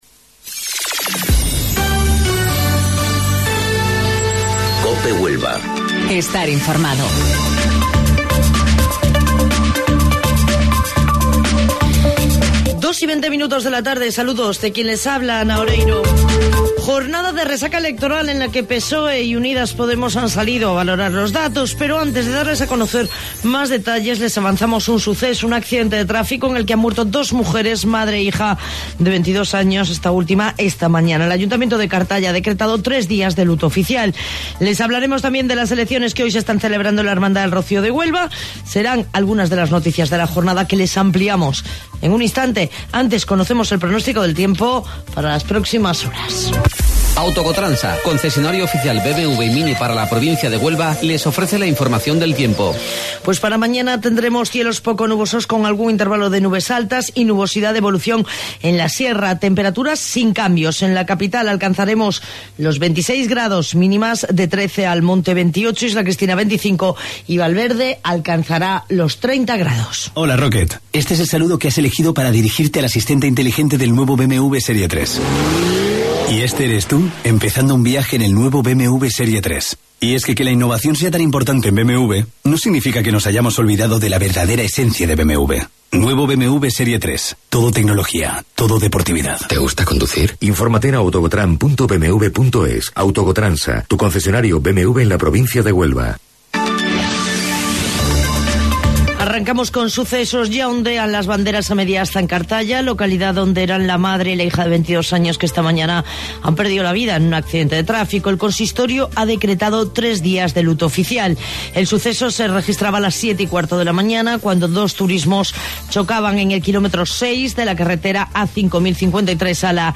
AUDIO: Informativo Local 14:20 del 29 de Abril